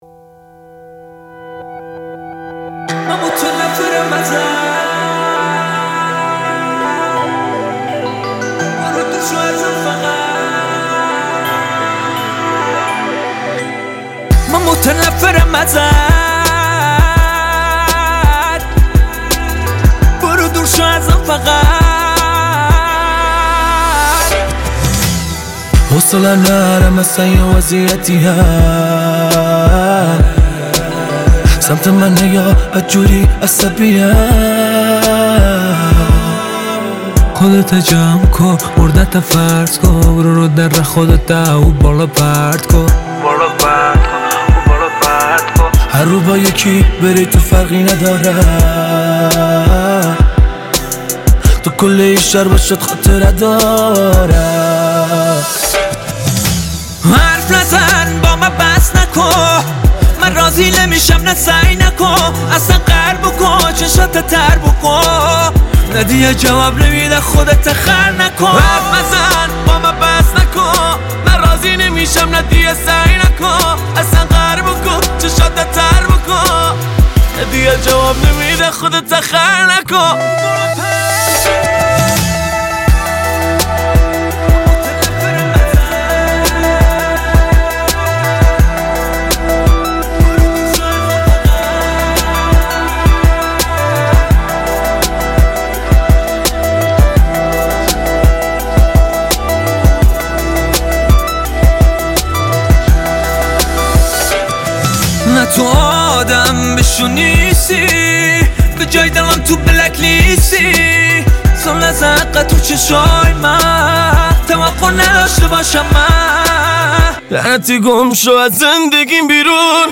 هیپ هاپ